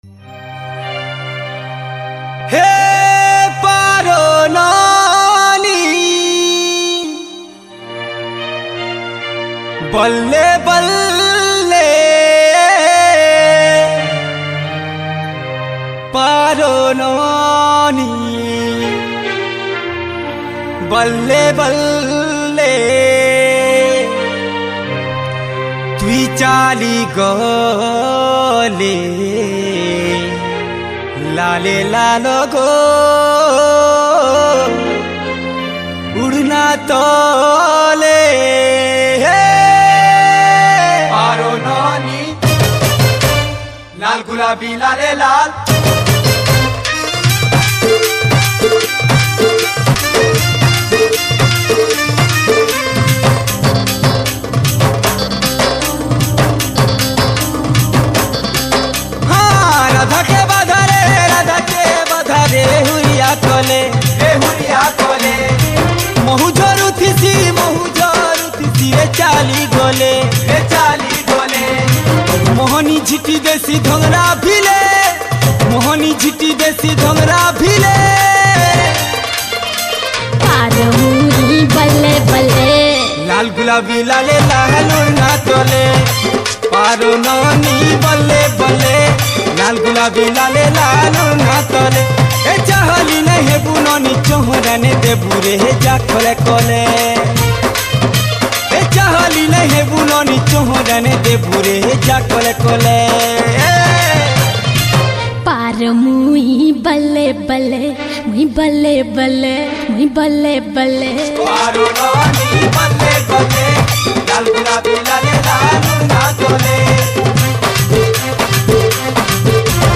Sambapuri Single Song 2022